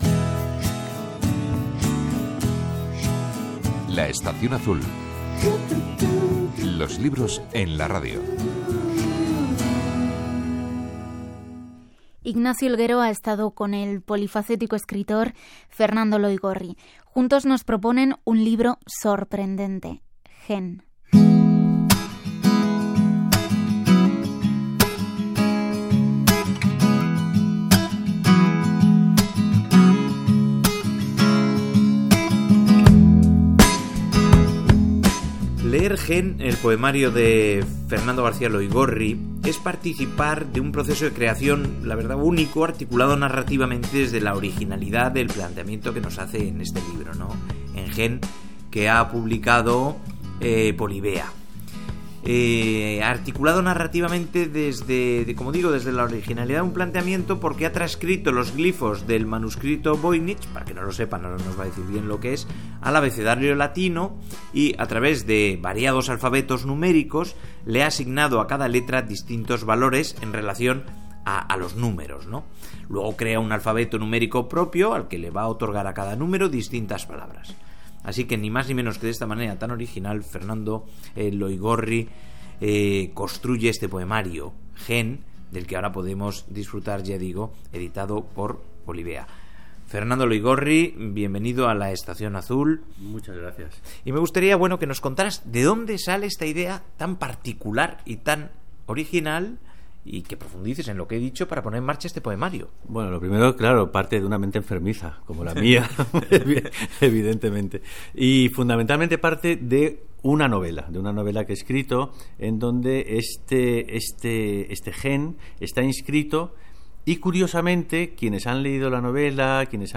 Entrevista emitida en el programa La Estación Azul de RNE
Esta es la entrevista.